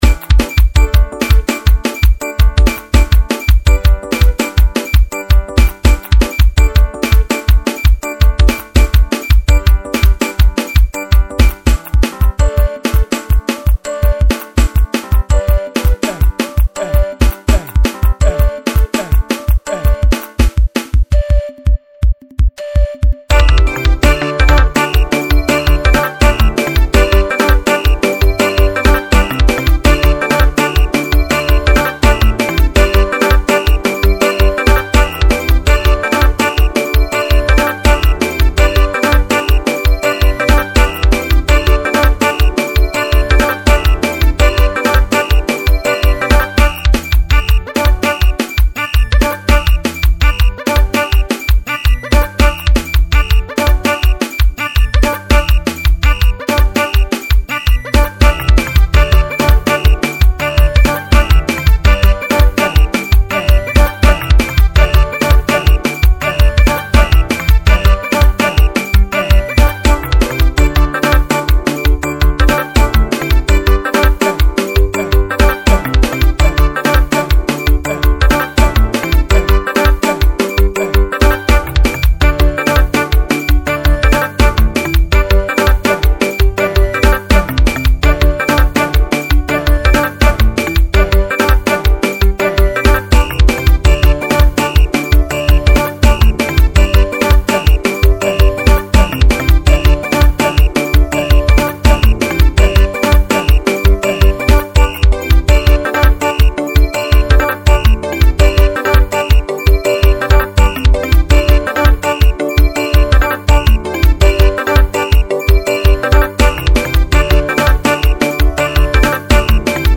04:27 Genre : Xitsonga Size